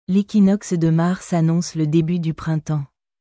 L’équinoxe de mars annonce le début du printempsレキノックス ドゥ マァルス アノンス ル デビュ デュ プランタォン